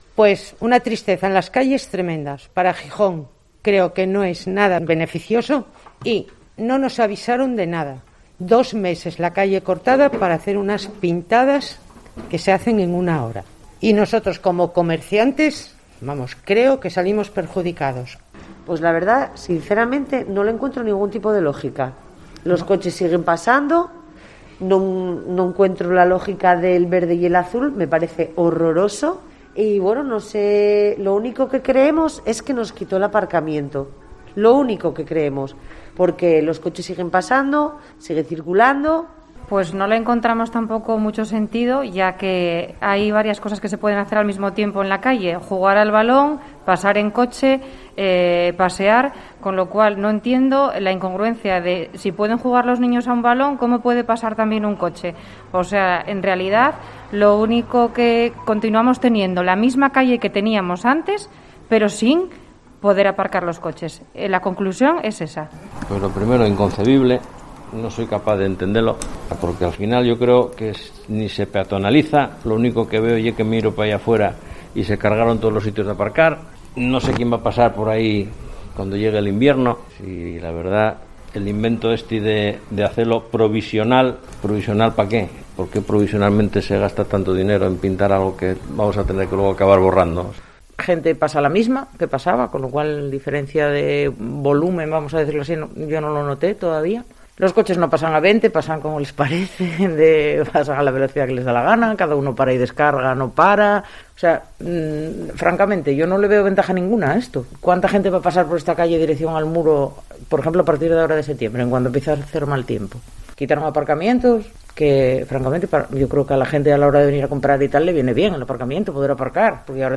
Opine comerciantes de la Calle Ruíz Gómez en Gijón